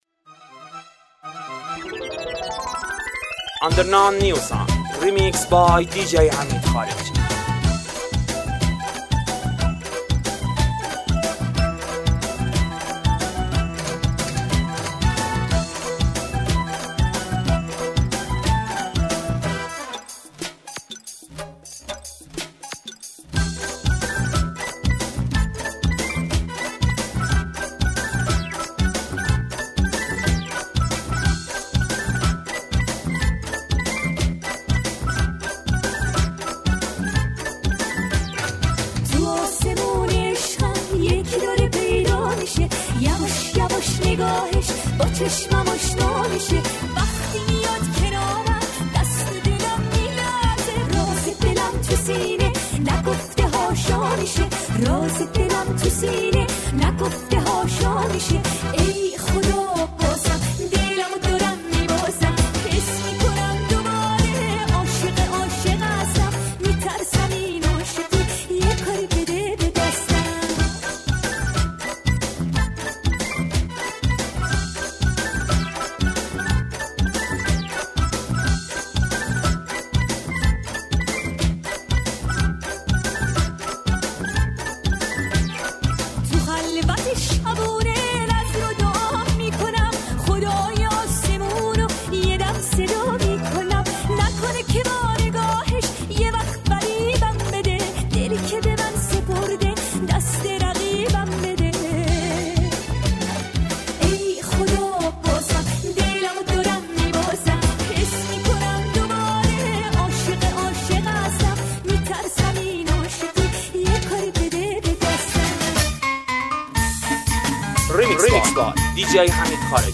ریمیکس شاد
مخصوص رقص